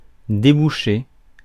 Ääntäminen
IPA: [de.bu.ʃe]